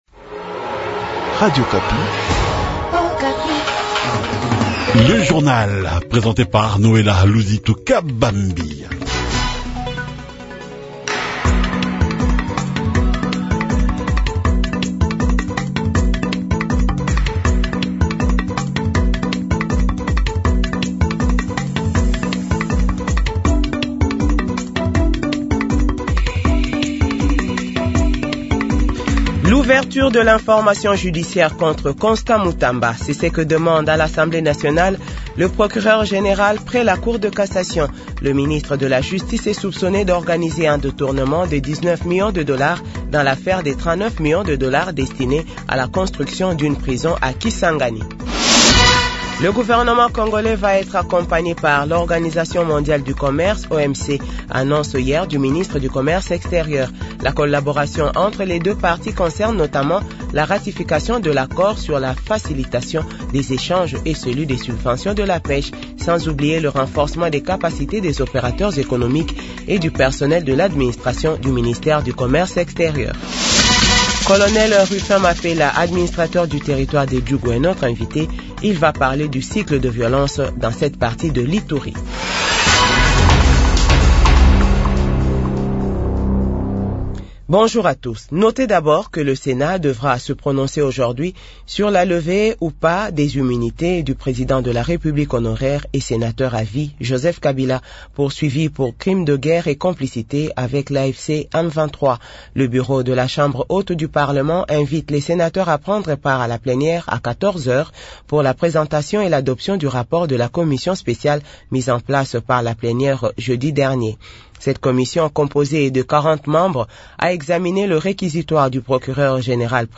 Journal 6h-7h